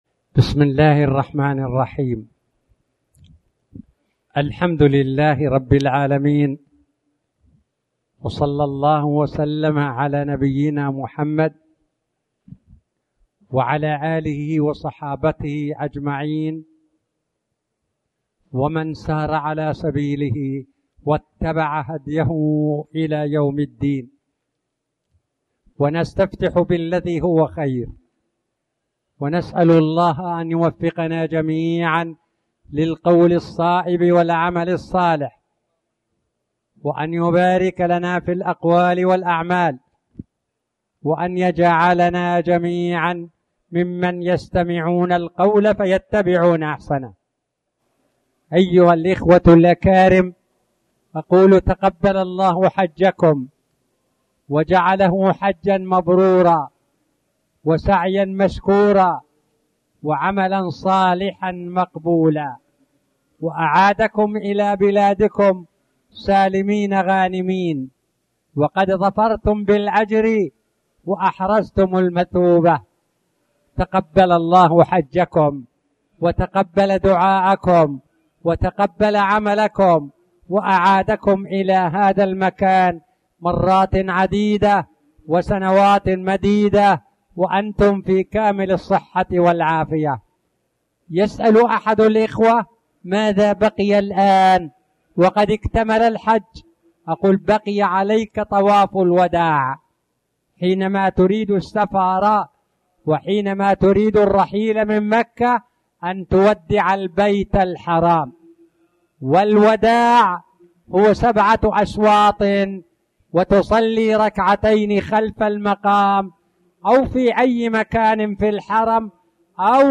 تاريخ النشر ٢٨ ذو الحجة ١٤٣٨ هـ المكان: المسجد الحرام الشيخ